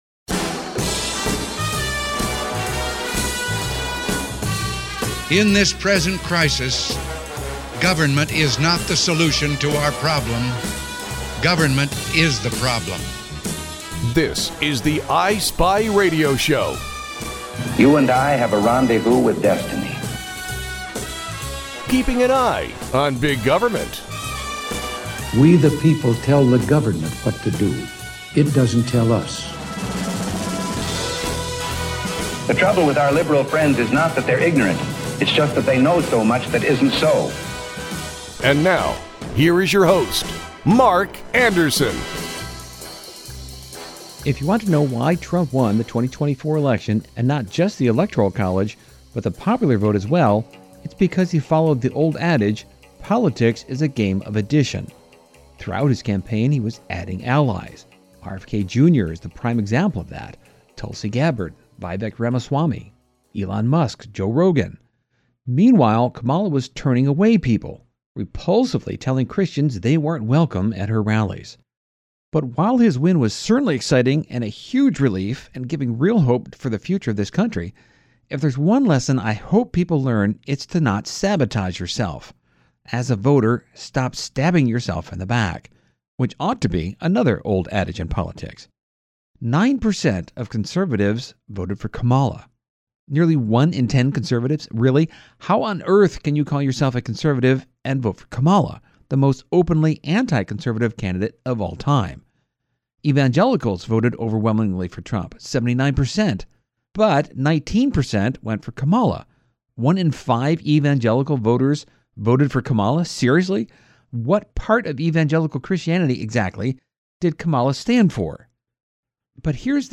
The I Spy Radio Show airs weekends, six different times, on seven different stations.